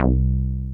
BASSFUNK 2.wav